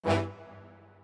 strings14_7.ogg